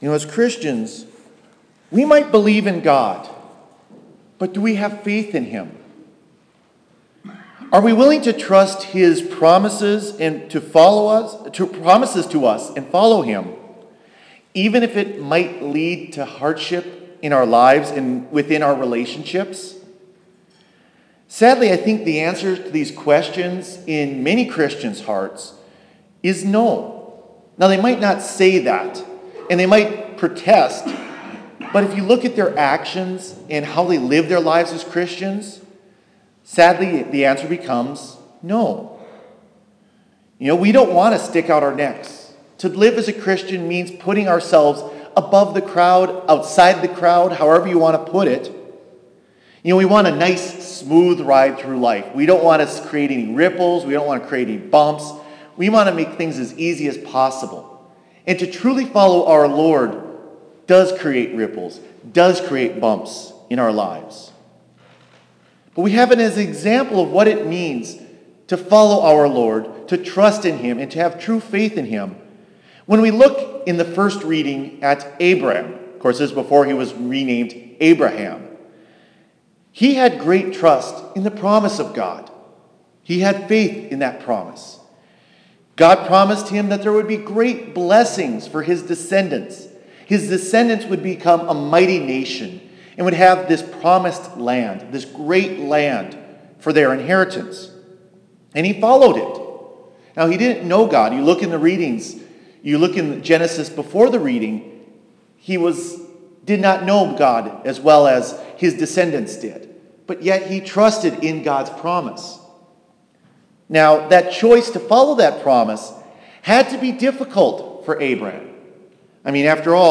Homily for the Second Sunday of Lent